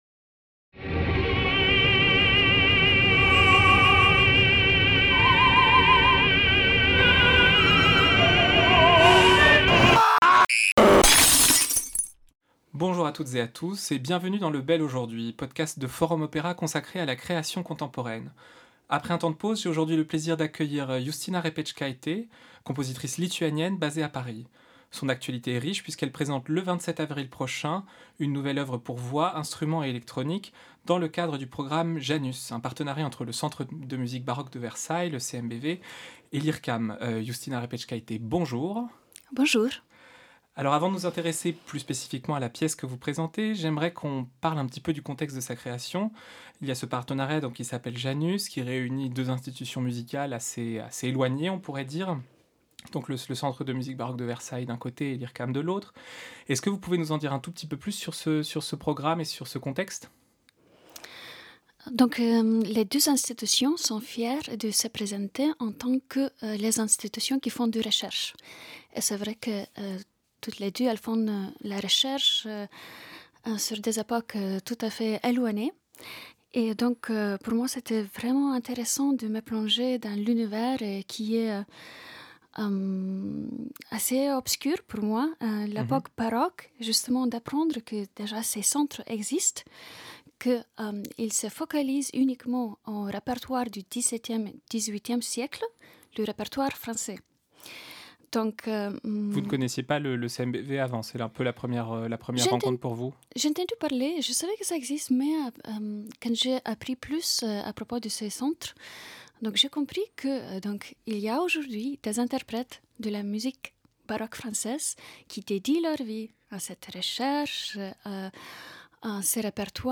Enregistré à Paris au Studio Mix in the City